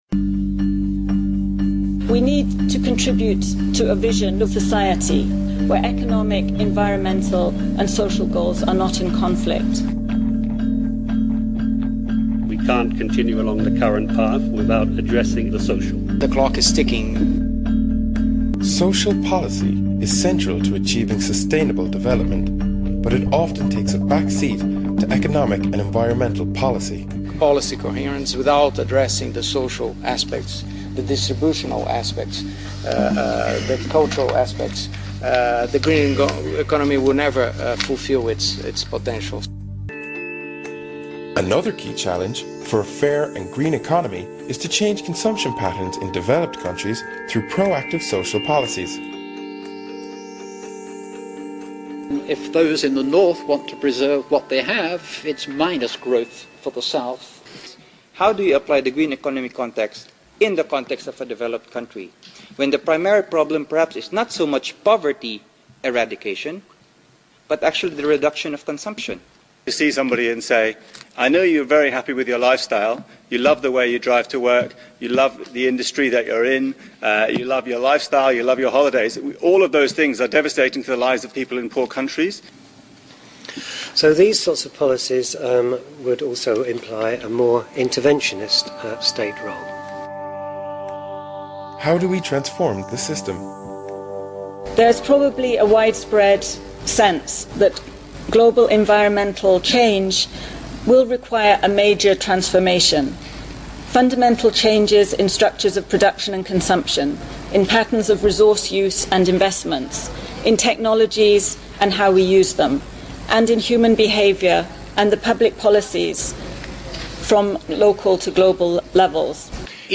UN JPG AWhat follows is an audio clip that represents an excerpt from one of the UNRISD videos.
Key phrases from the UNRISD discussion:
un-sd21-discussion.mp3